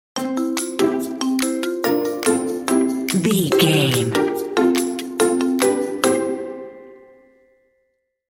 Mixolydian
playful
orchestral
percussion
pizzicato
marimba
xylophone
medium tempo
harp
violin